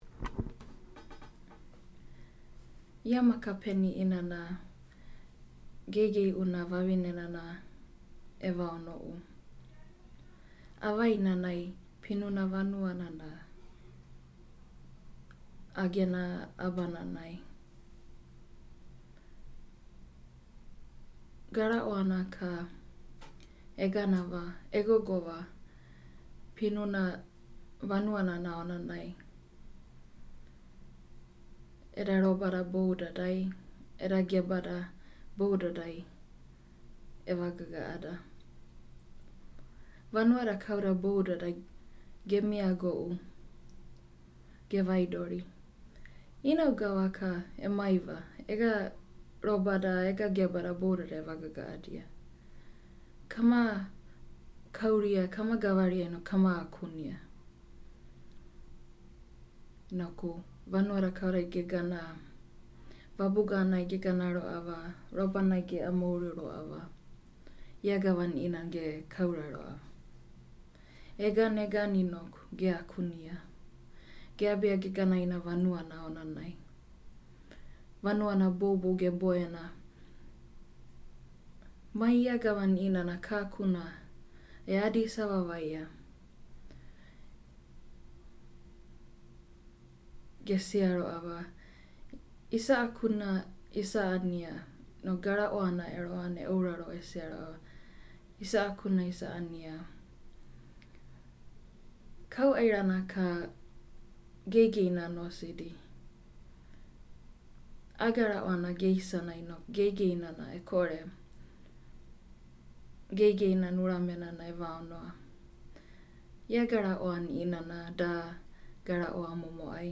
Eel-Story.mp3